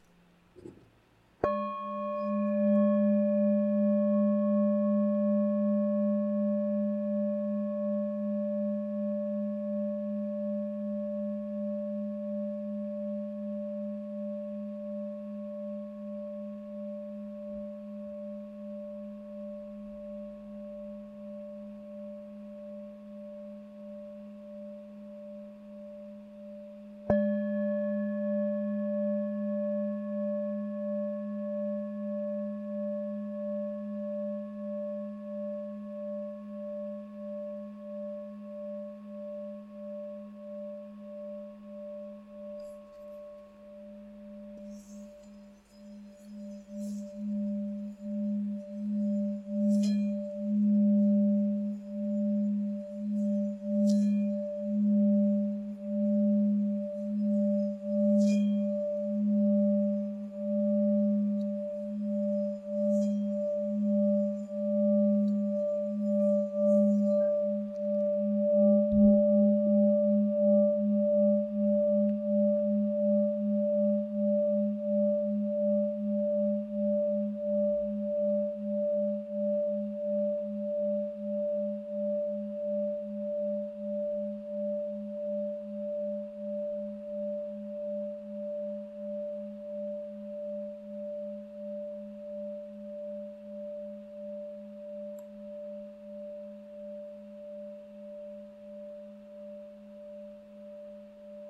Campana tibetana originale
Nota Armonica RE(D) 5 585 HZ
Nota di fondo SOL(G) #3 204 HZ
Campana Tibetana Nota SOL(G) #3 204 HZ